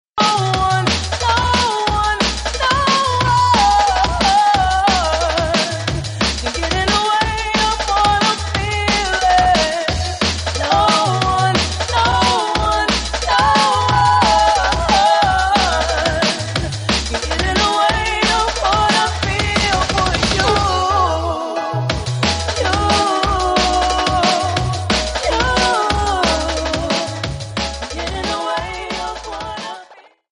TOP > Vocal Track